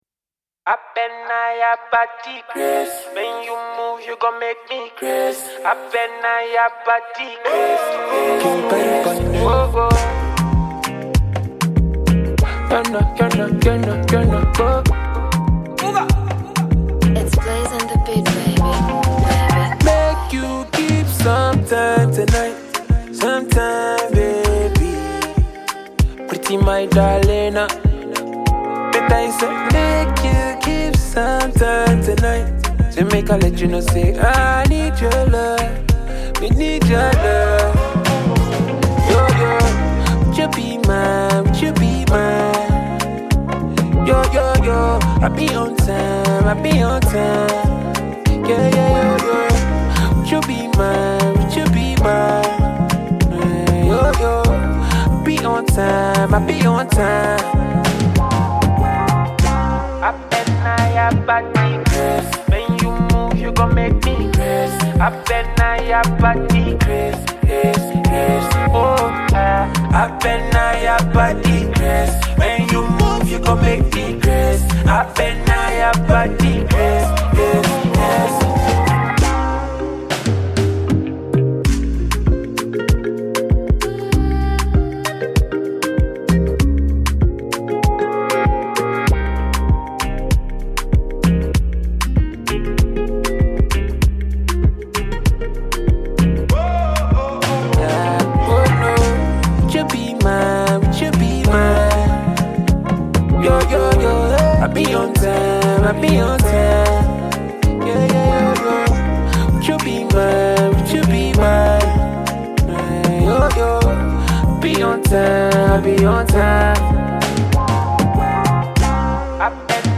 Record a verse on the free instrumental